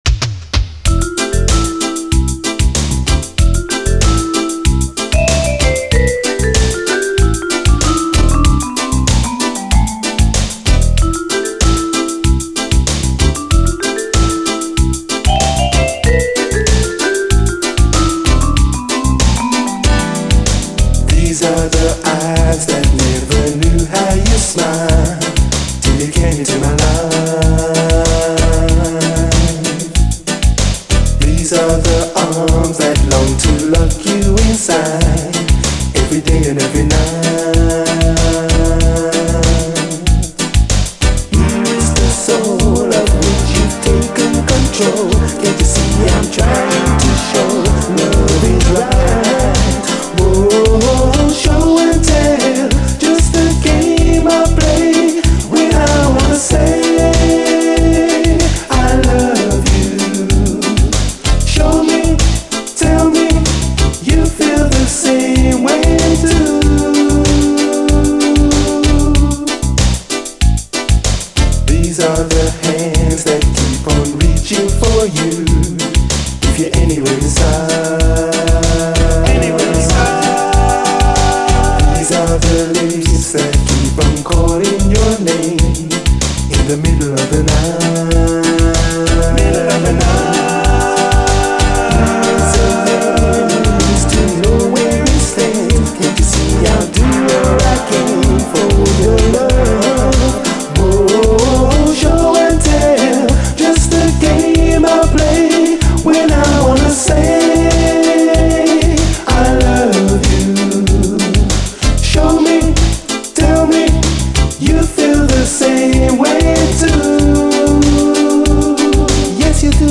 REGGAE
90’Sシンセ・ディスコ・レゲエ・カヴァー収録！
90’Sシンセ・サウンドとコーラス・ハーモニーが溶け合って非常にお洒落です。
どちらもインストも収録。